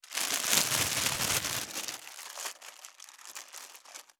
655コンビニ袋,ゴミ袋,スーパーの袋,袋,買い出しの音,ゴミ出しの音,袋を運ぶ音,
効果音